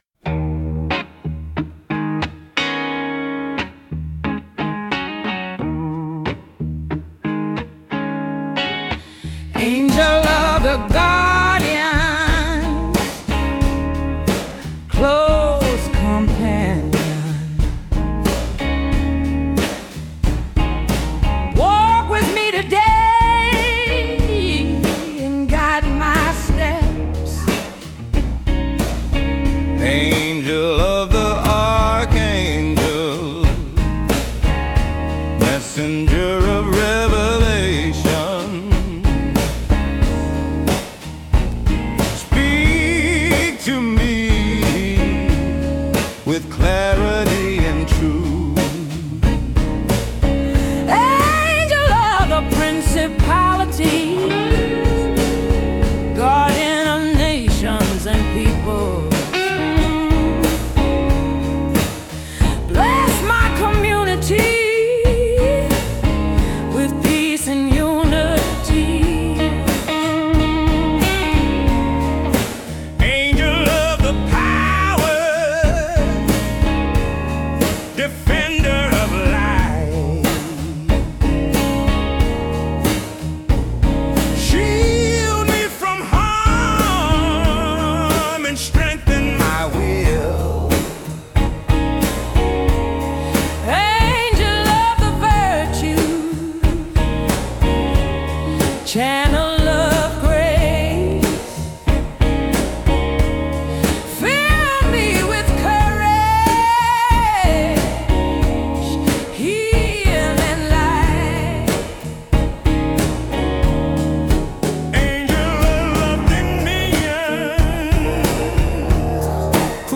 These angelic intonations are decidedly NOT petitions for help!
Think of it as temple incense in sonic form — a gift of vibration, devotion, and clarity offered into the highest reaches of Being.